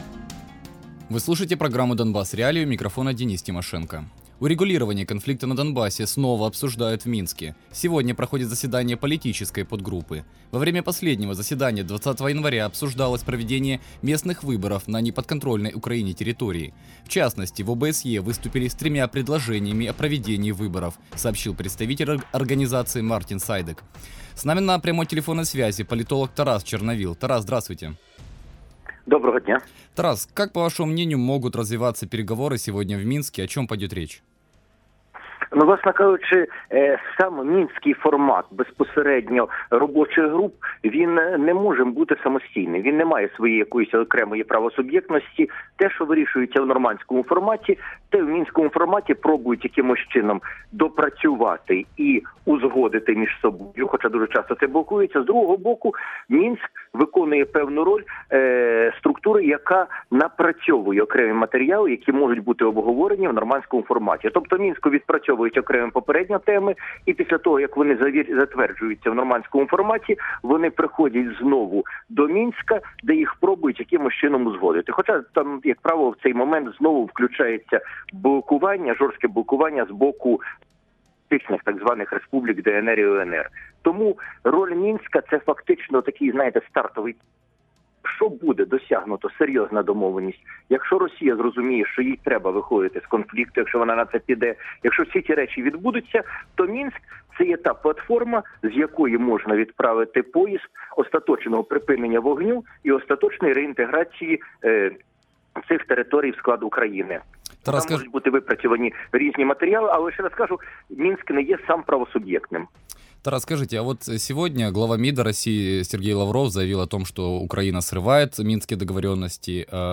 Таку думку в ефірі Радіо Свобода висловив політолог Тарас Чорновіл.